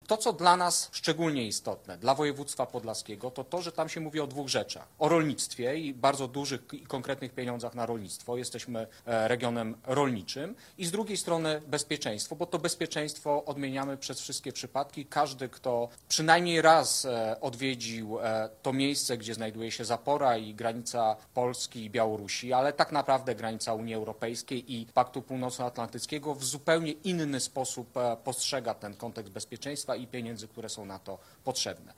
Na dopłaty dla rolników zostanie przeznaczone 300 mld euro, a jest to szczególnie istotne dla województwa podlaskiego, mówił wojewoda podlaski Jacek Brzozowski.